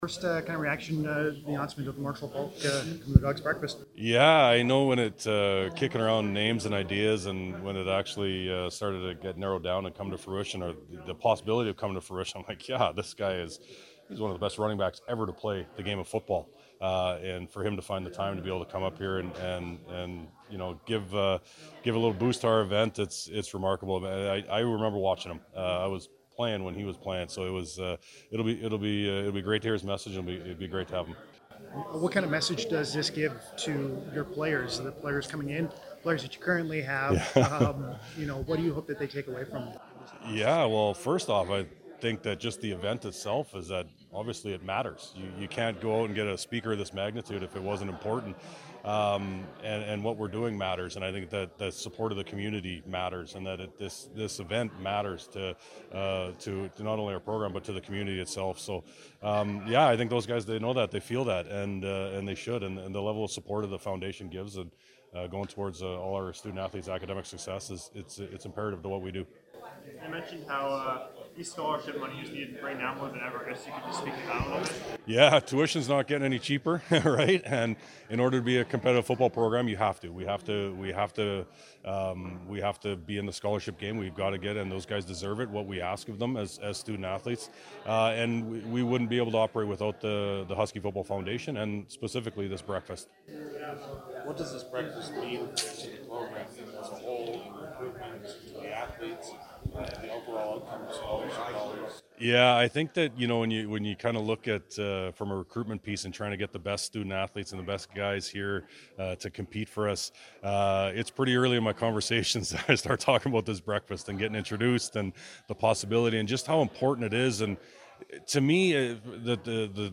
He spoke to reporters after the announcement.